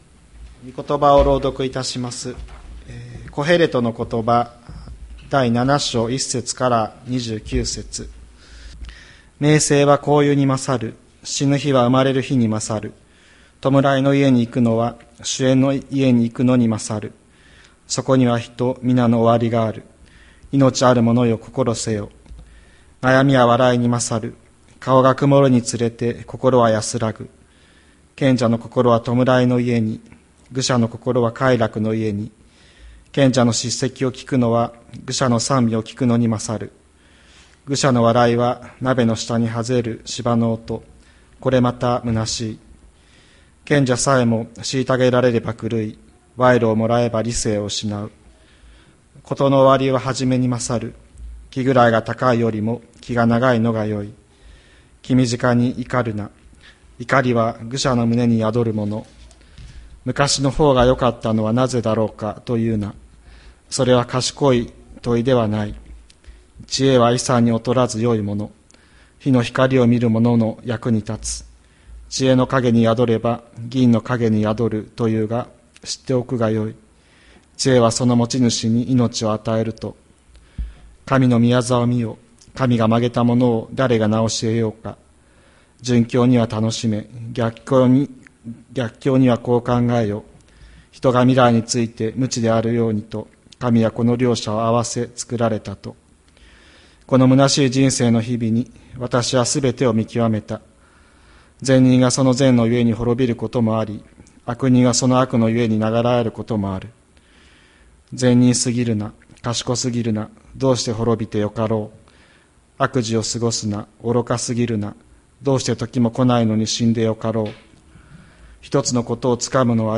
2024年10月27日朝の礼拝「生まれる日と死ぬ日」吹田市千里山のキリスト教会
千里山教会 2024年10月27日の礼拝メッセージ。